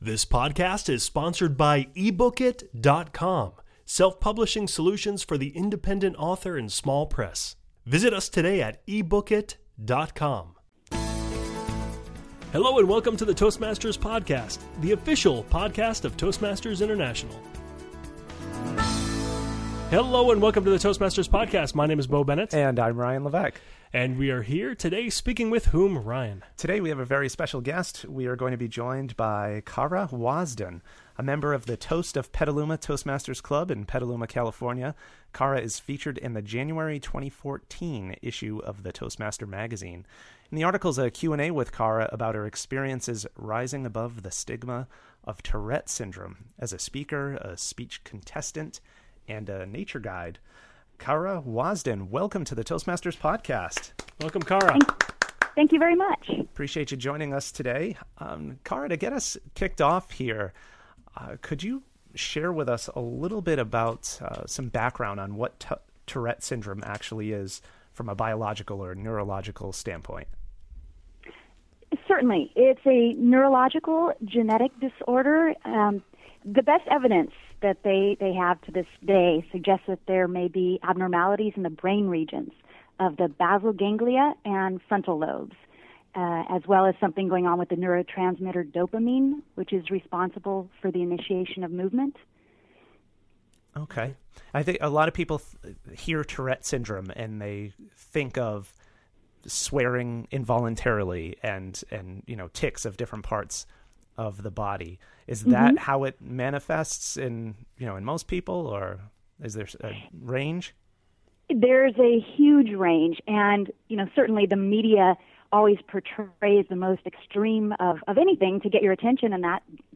In this short interview